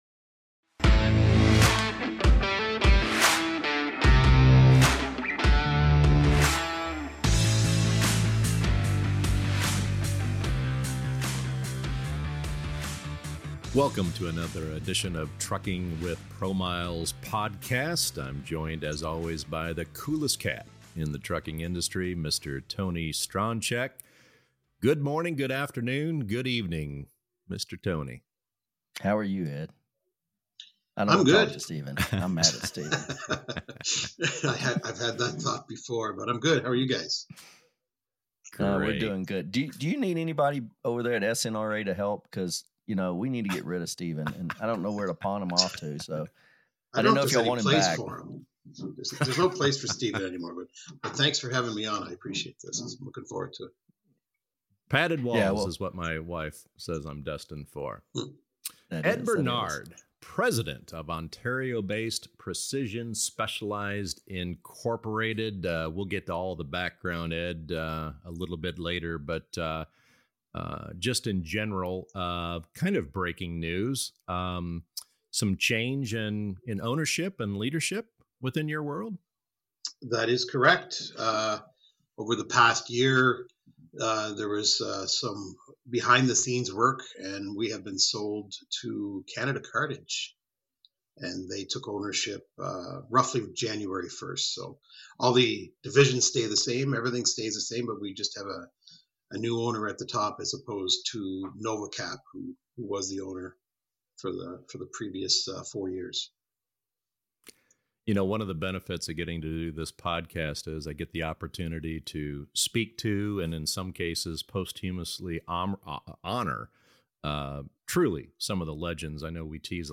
A Discussion With IFTA, Inc. The Past